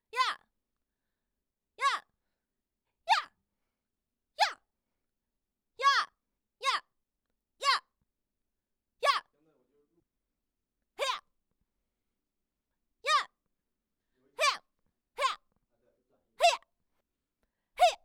呀.wav 0:00.00 0:18.05 呀.wav WAV · 1.5 MB · 單聲道 (1ch) 下载文件 本站所有音效均采用 CC0 授权 ，可免费用于商业与个人项目，无需署名。
人声采集素材/女激励/呀.wav